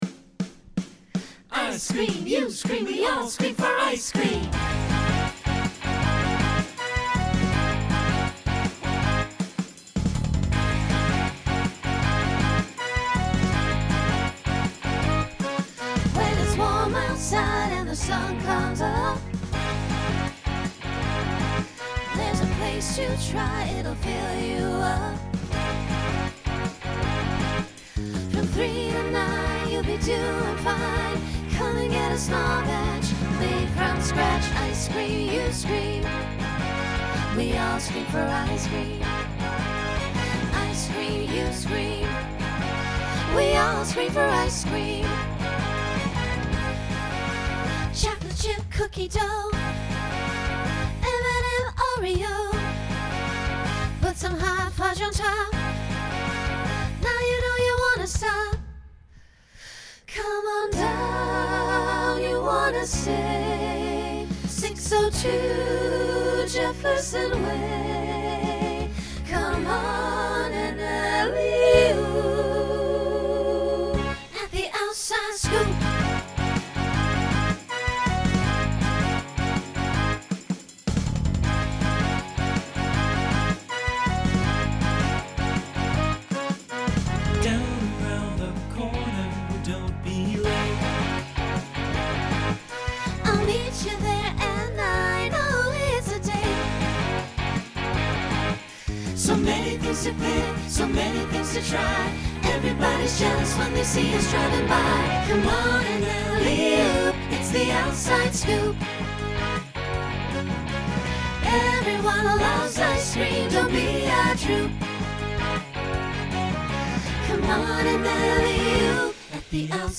Genre Rock
Transition Voicing SATB